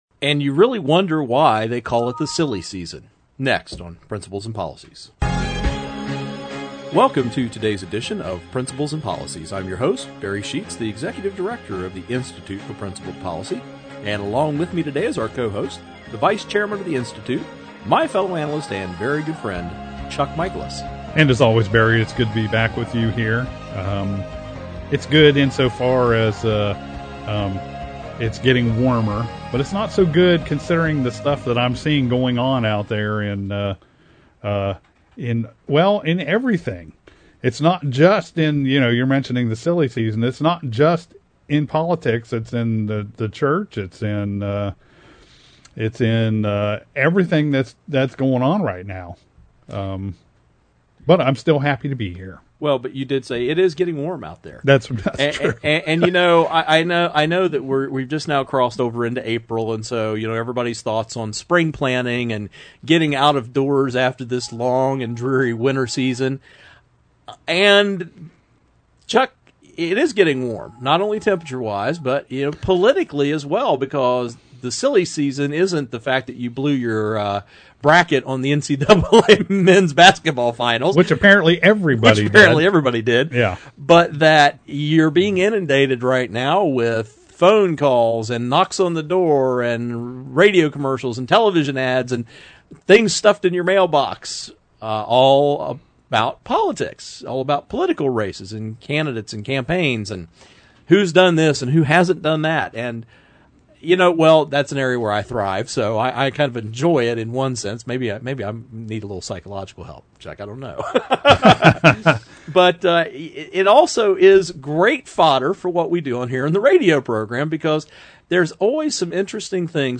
Our Principles and Policies radio show for Saturday AprilÂ 5, 2014.